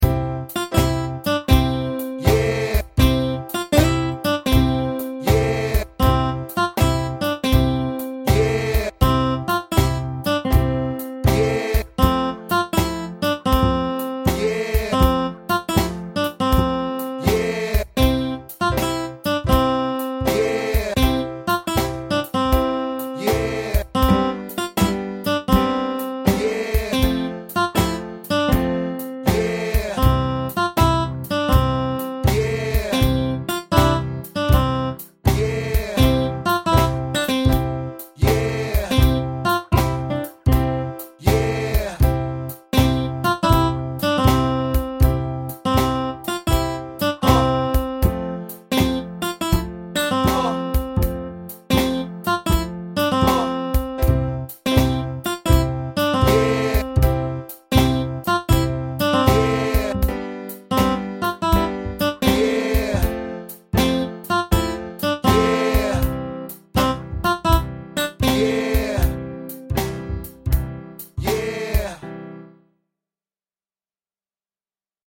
Blues track with drums